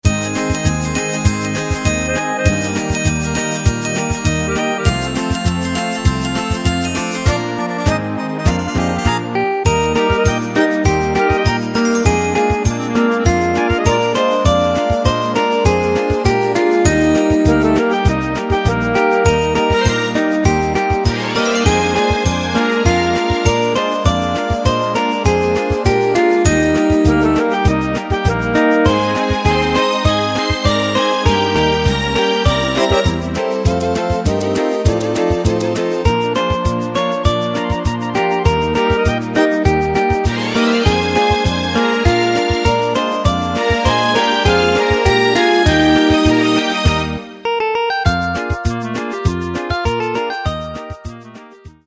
Takt:          4/4
Tempo:         100.00
Tonart:            Eb
Schlager-Oldie aus dem Jahr 1955!
Playback mp3 Demo